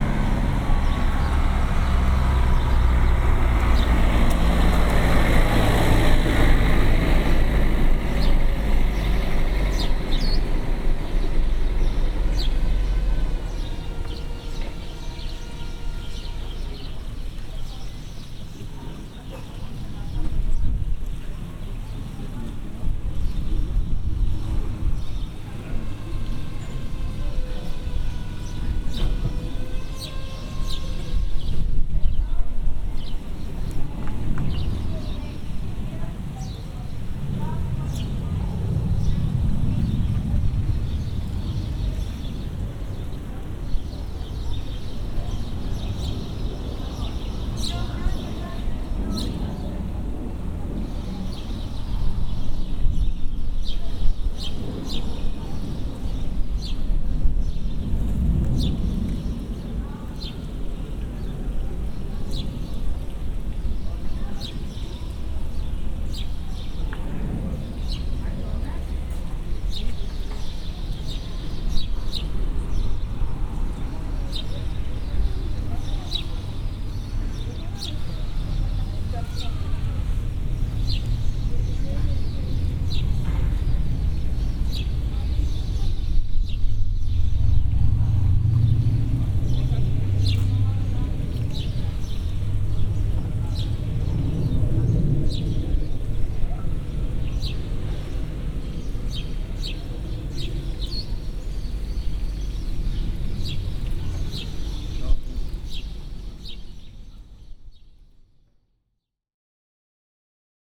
Teguise_Marktplatz_2.mp3